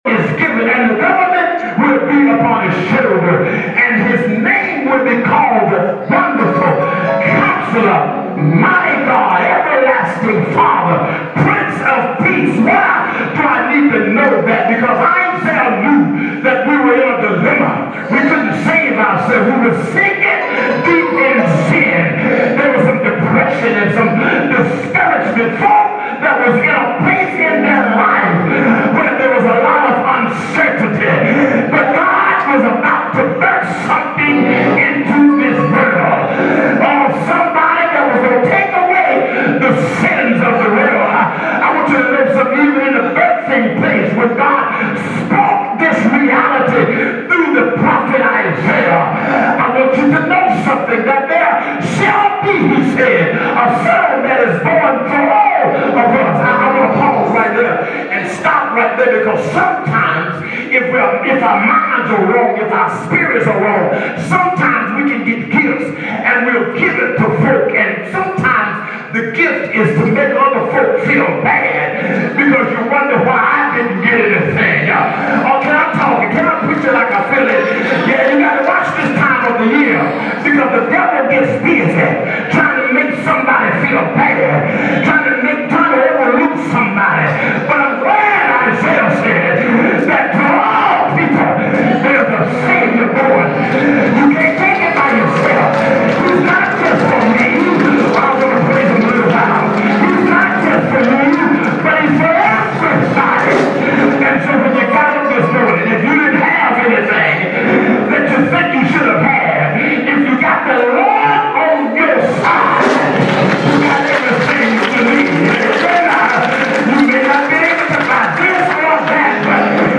Mt. Sinai Missionary Baptist Church Sermons